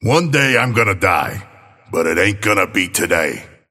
Abrams voice line - One day, I'm gonna die.